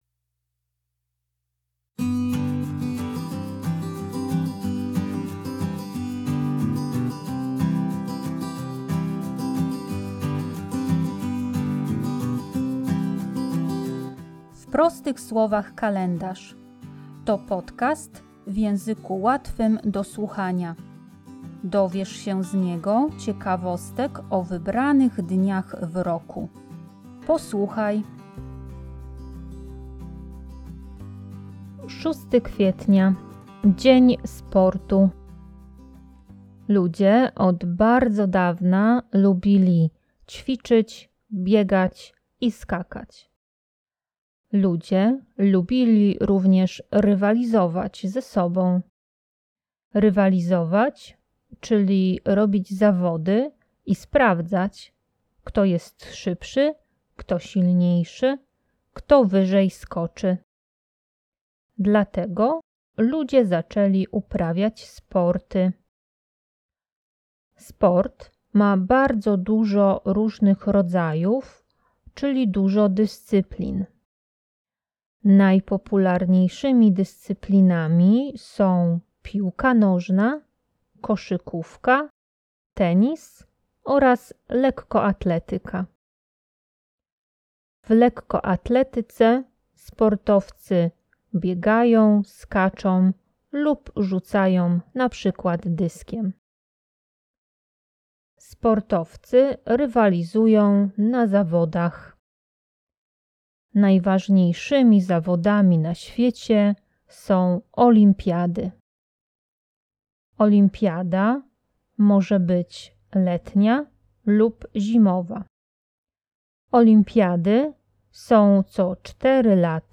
W podcaście usłyszycie dźwięki kibicowania.